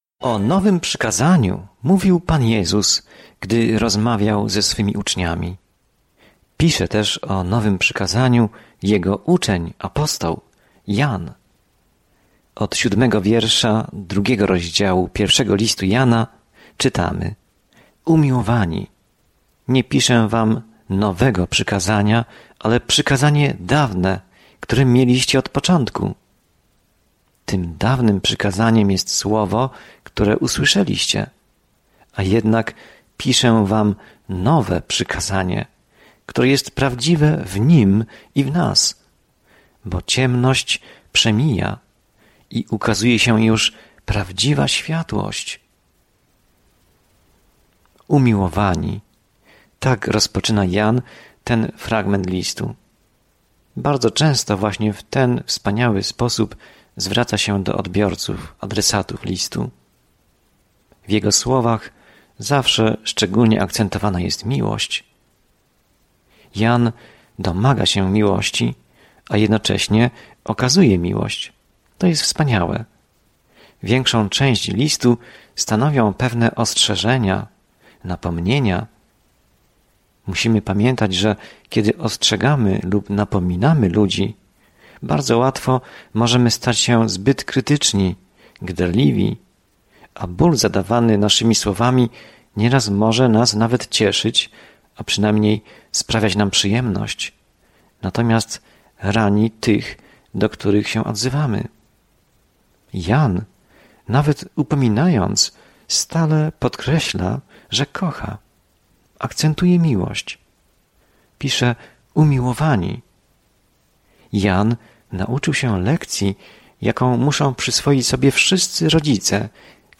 Codziennie podróżuj po 1 Liście Jana, słuchając studium audio i czytając wybrane wersety ze słowa Bożego.